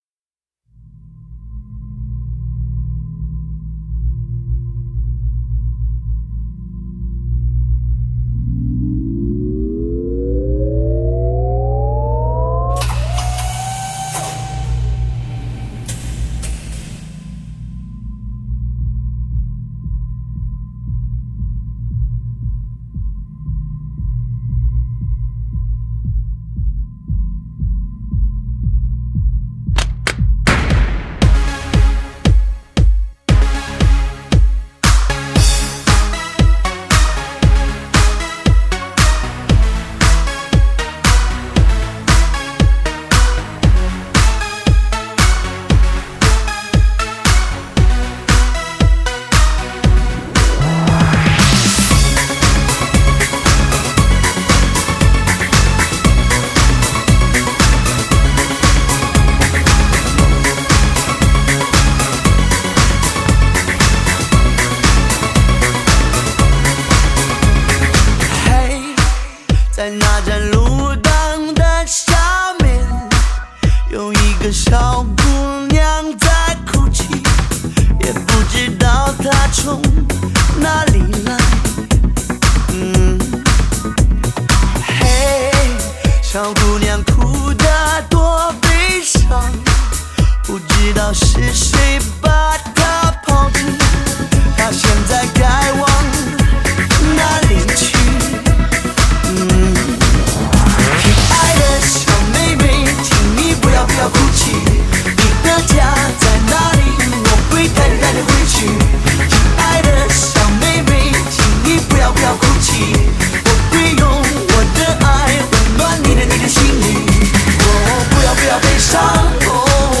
高级音响专用发烧大碟
5D音效360度环绕HIFI汽车专用大碟。